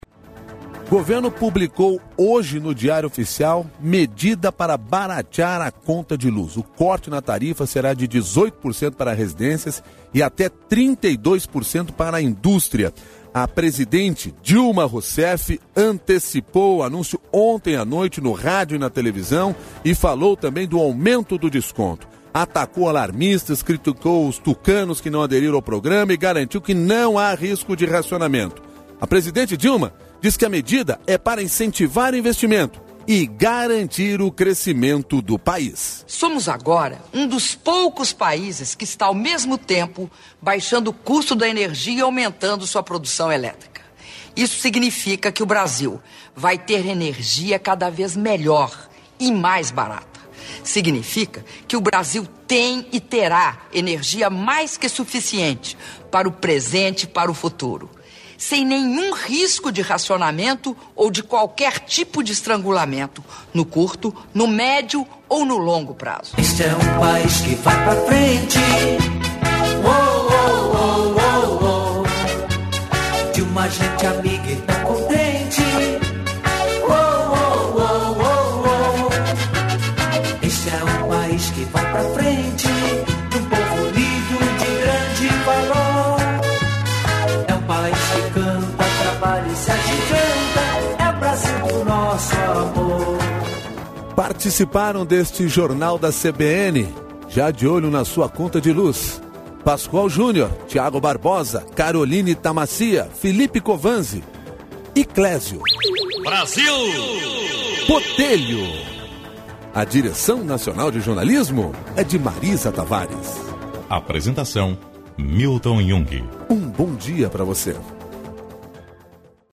Ouça a charge do Jornal